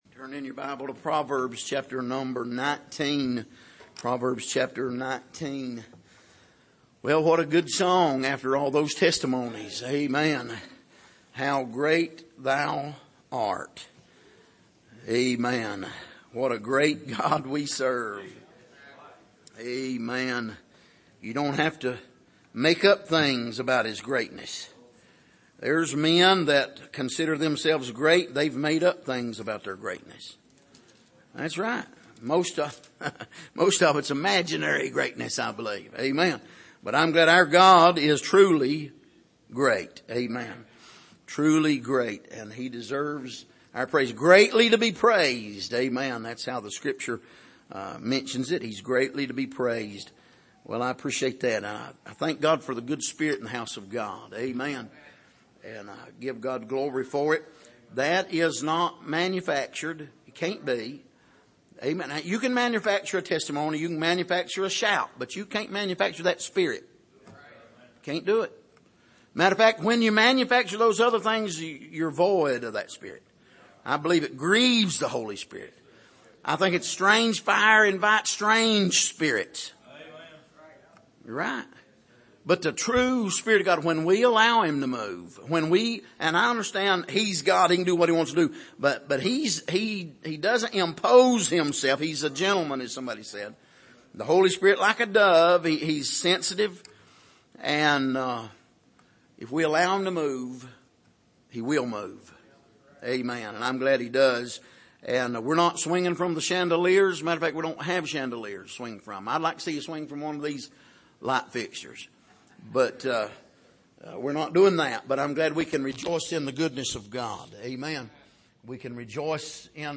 Passage: Proverbs 19:1--7 Service: Sunday Evening